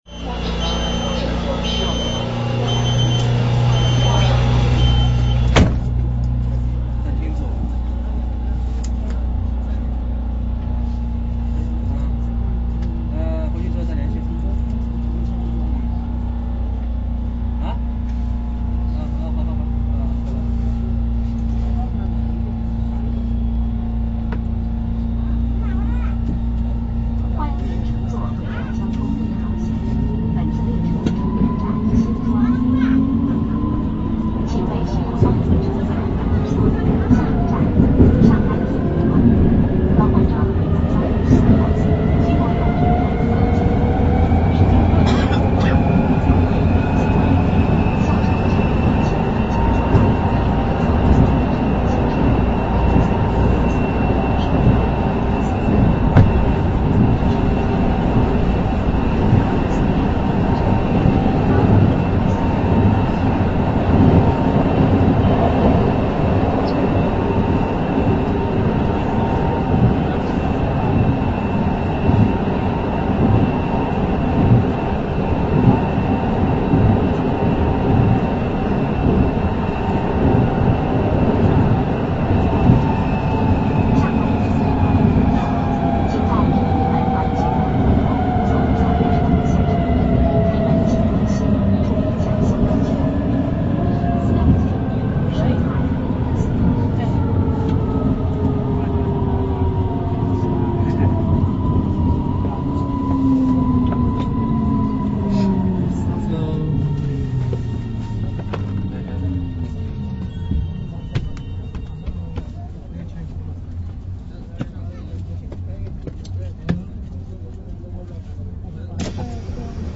1号線DC01B型電車走行音（上海体育館→漕宝路）